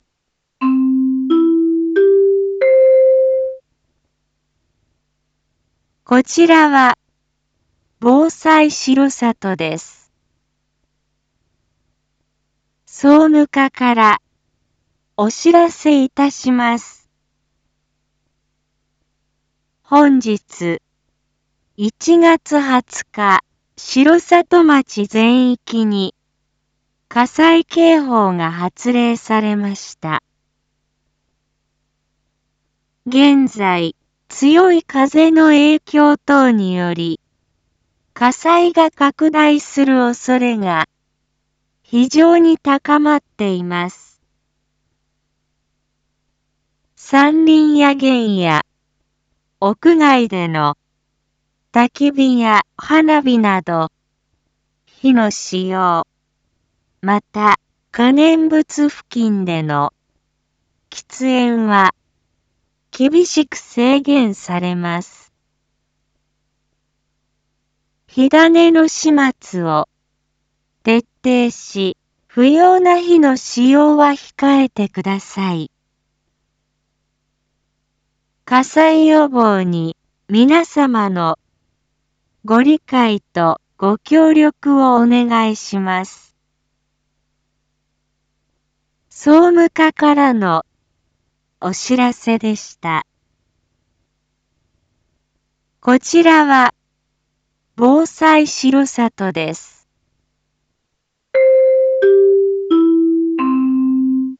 Back Home 一般放送情報 音声放送 再生 一般放送情報 登録日時：2026-01-20 10:31:51 タイトル：火の取り扱いにご注意ください！（火災警報発令中） インフォメーション：火の取り扱いにご注意ください！（火災警報発令中） 令和8年1月20日9時00分現在、空気が乾燥し、火災が発生しやすい状況のため、城里町の全域に火の使用を制限する情報が発令されました。